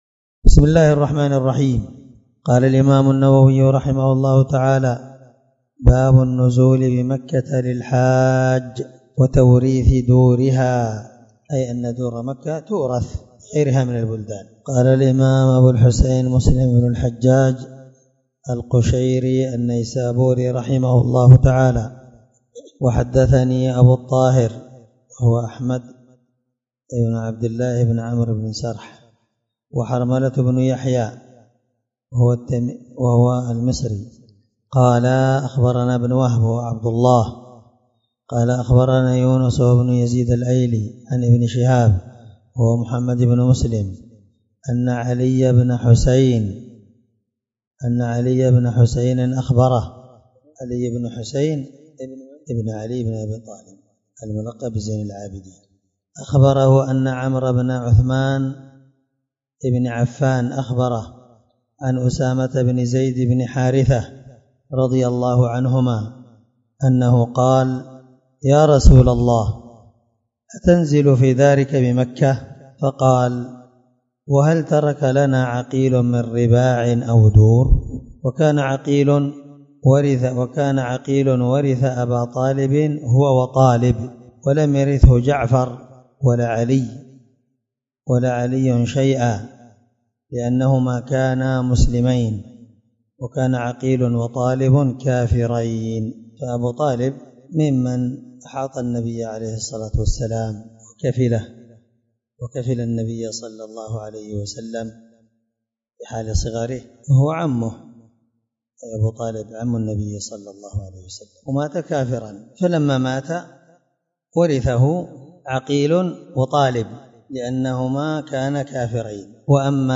الدرس79من شرح كتاب الحج حديث رقم(1351) من صحيح مسلم